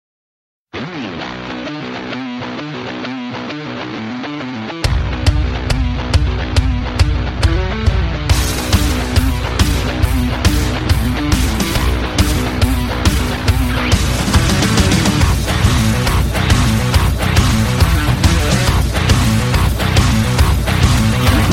Проигрыш на гитаре и ударных